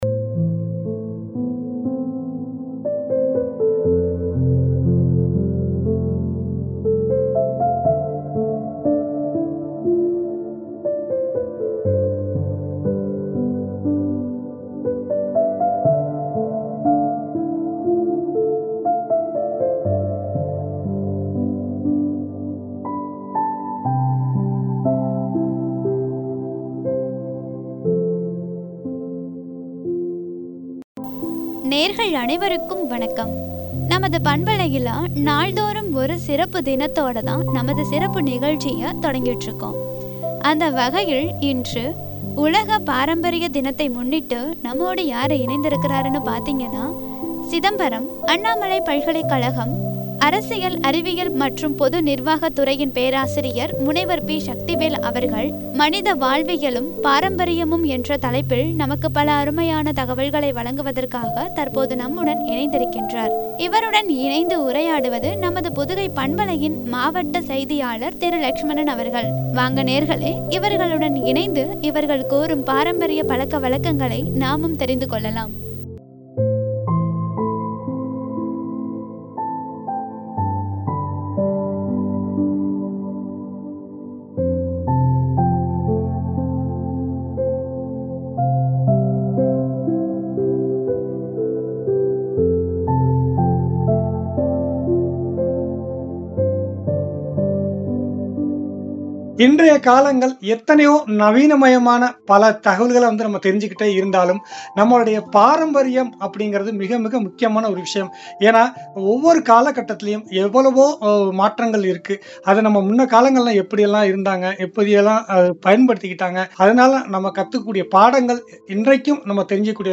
பாரம்பரியமும்” என்ற தலைப்பில் வழங்கிய உரையாடல்.